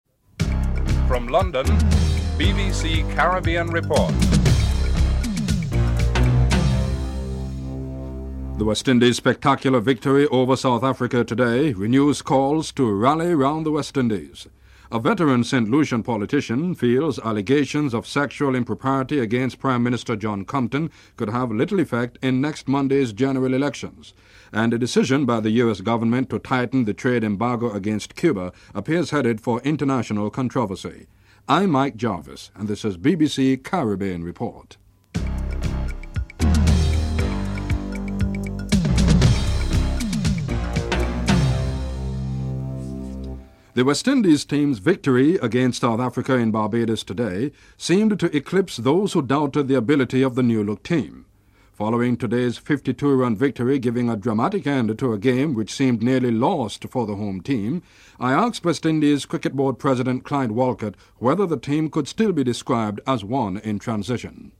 1. Headlines (00:00-00:00)
2. The West Indies Cricket Team beats South Africa with a 52 run victory in Barbados. West Indies Cricket Board President Clyde Walcott comments on the team's transitioning (00:43-02:36)